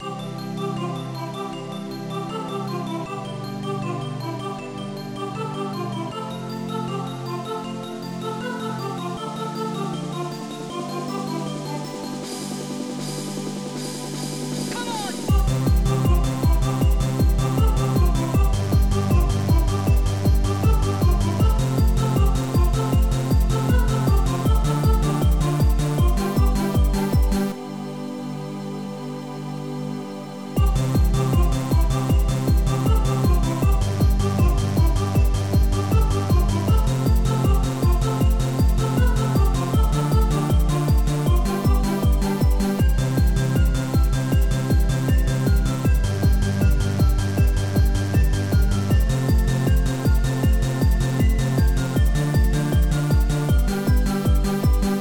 Extended Module
Tracker FastTracker v2.00 XM 1.04